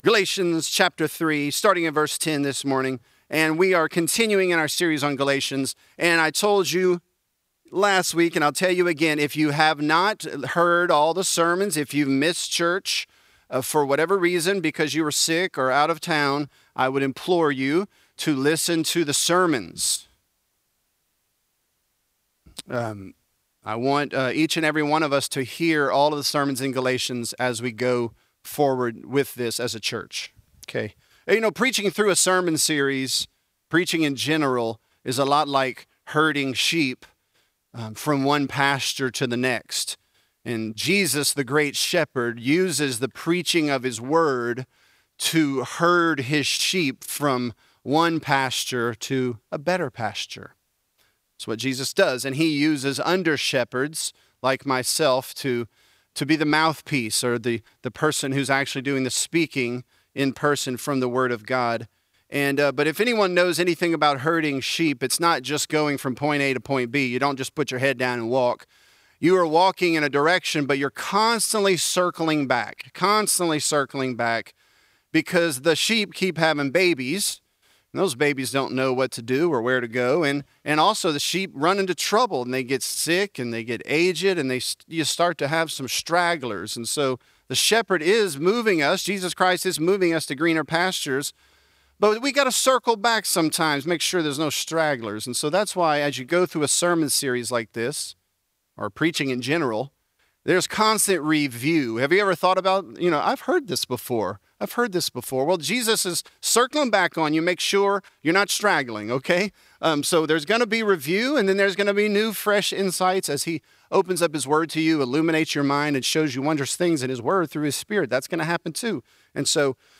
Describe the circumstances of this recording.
This is a part of our sermon series on the book of Galatians. Christ Church Lafayette